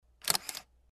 camera1.mp3